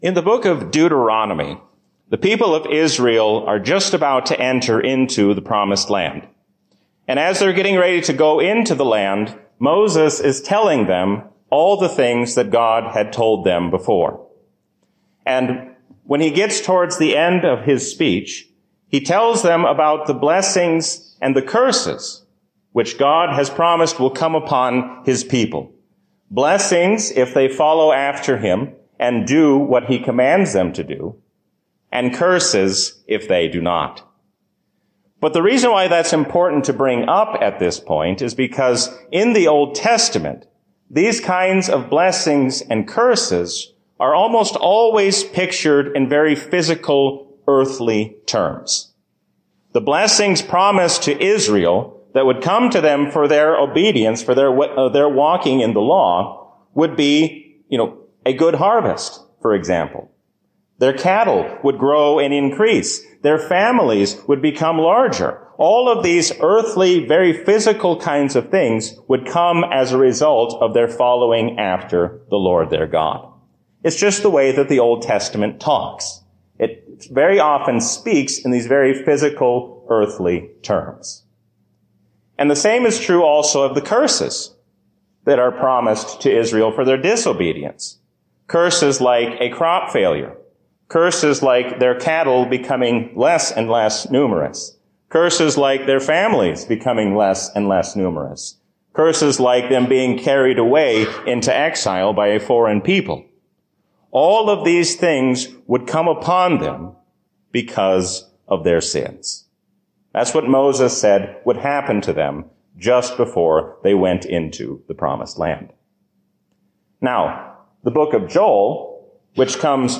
A sermon from the season "Lent 2020."